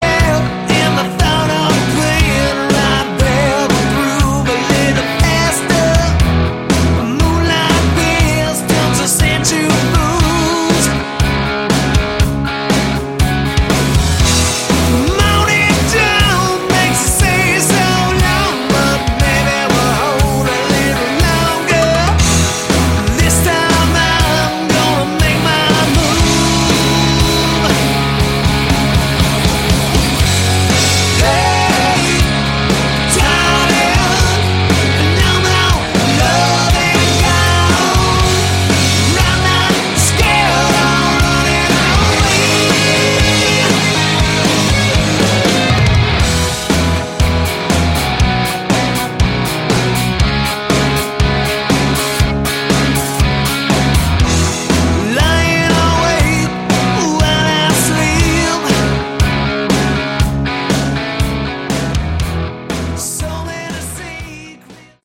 Category: Hard Rock
Guitar, Backing Vocals
Bass, Backing Vocals
Drums